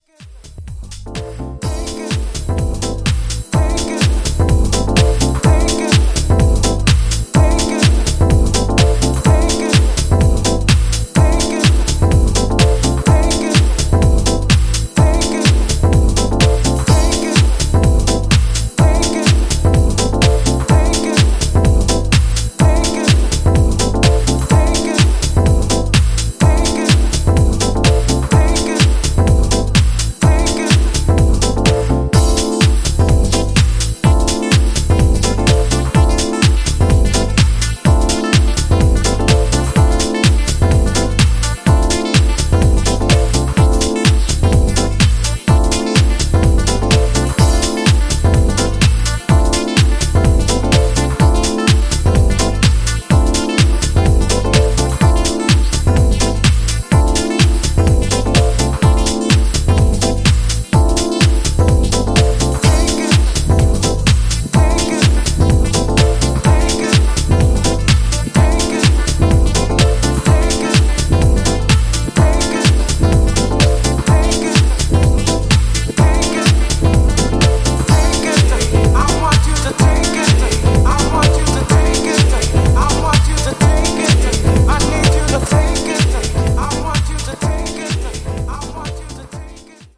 B1: Dub Mix